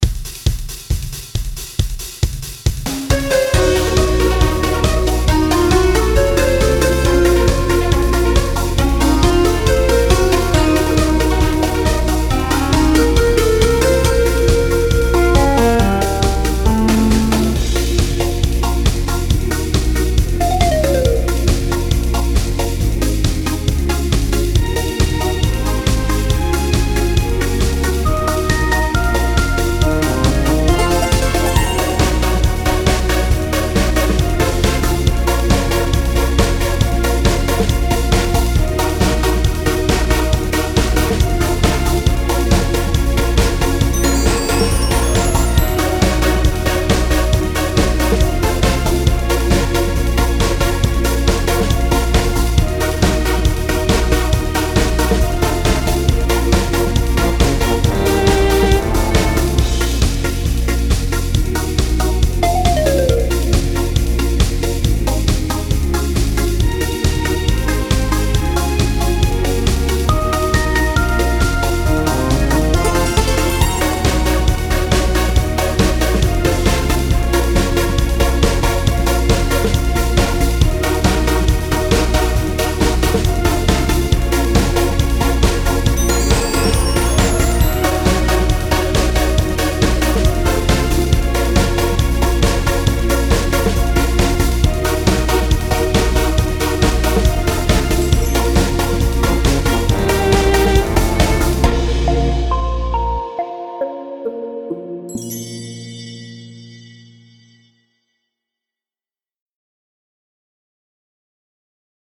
Главная / Песни для детей / Песни про маму
Слушать или скачать минус